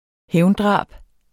Udtale [ ˈhεwnˌdʁɑˀb ]